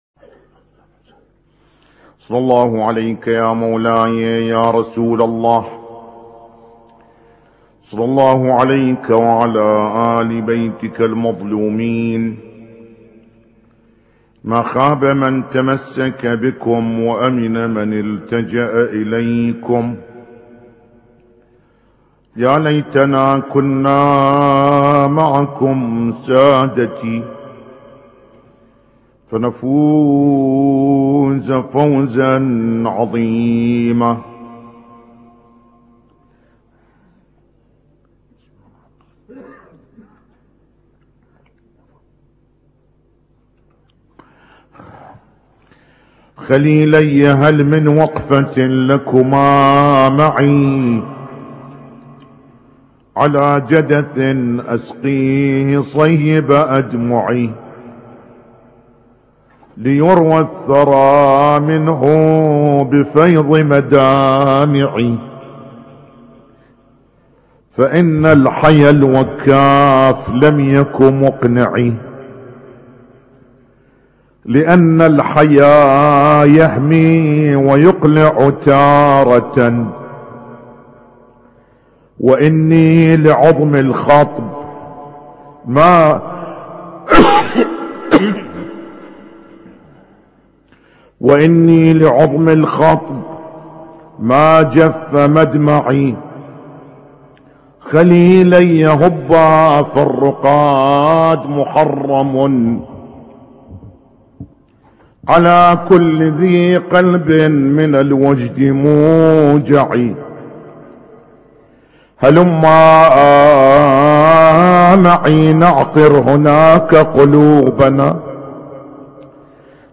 اسم التصنيف: المـكتبة الصــوتيه >> المحاضرات >> المحاضرات الاسبوعية ما قبل 1432